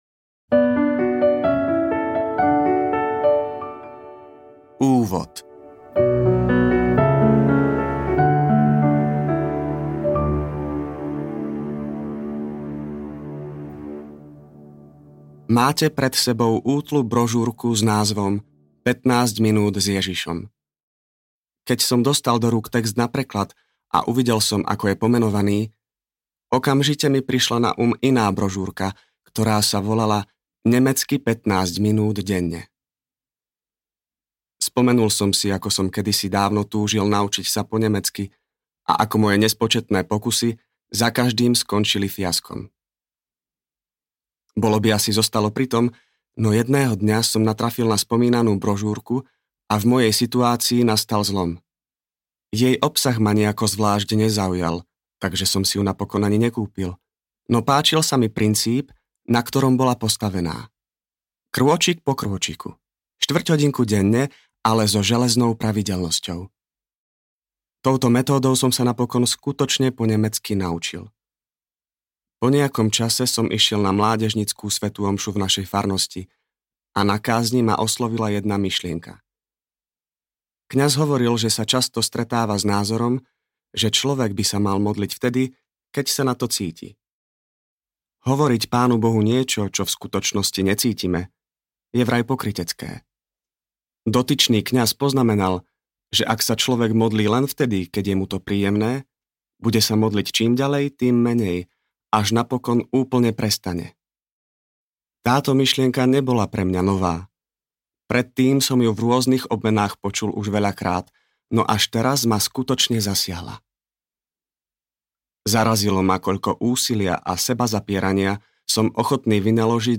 15 minút s Ježišom audiokniha
Ukázka z knihy